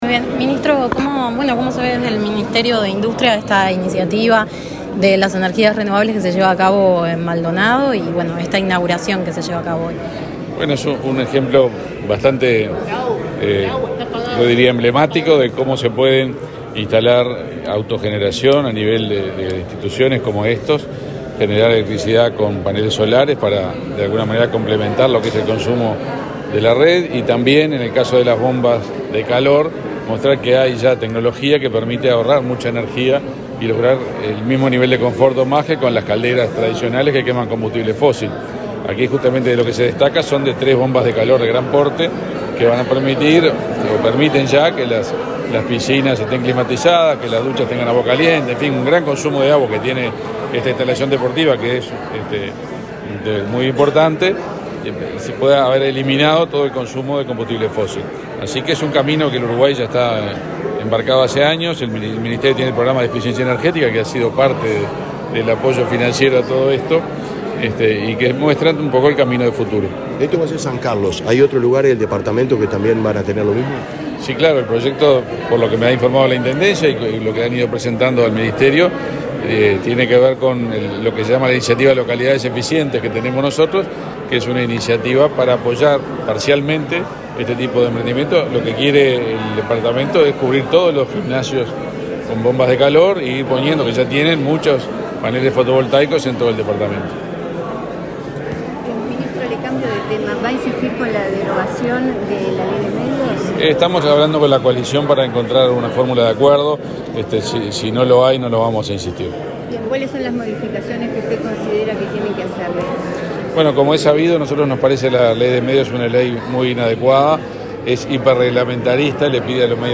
Declaraciones del ministro de Industria, Omar Paganini
Declaraciones del ministro de Industria, Omar Paganini 04/08/2023 Compartir Facebook X Copiar enlace WhatsApp LinkedIn El ministro de Industria, Omar Paganini, dialogó con la prensa, antes de participar en el acto de inauguración de 10 plantas fotovoltaicas y bombas de calor en el departamento de Maldonado.